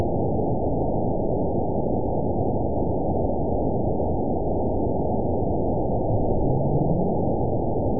event 921470 date 10/16/24 time 01:05:21 GMT (6 months, 2 weeks ago) score 9.48 location TSS-AB08 detected by nrw target species NRW annotations +NRW Spectrogram: Frequency (kHz) vs. Time (s) audio not available .wav